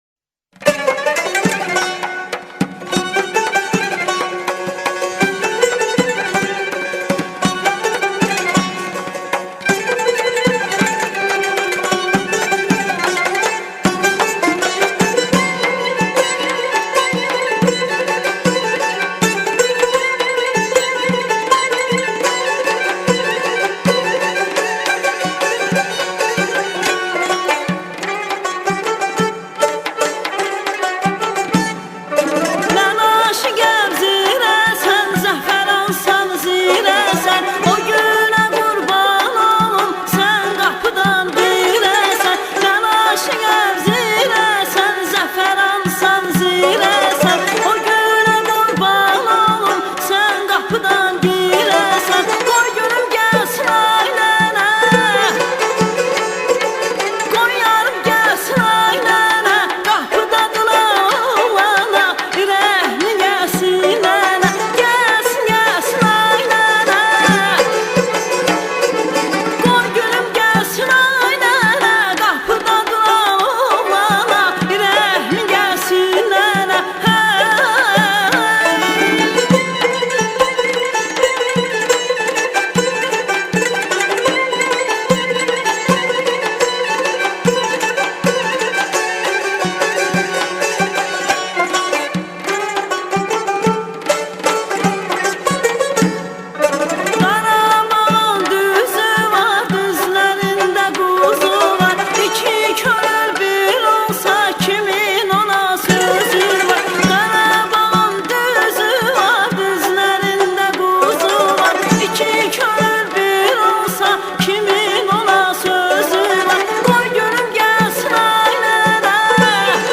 دسته بندی : موزیک آذربایجانی تاریخ : دوشنبه ۲ خرداد ۱۴۰۱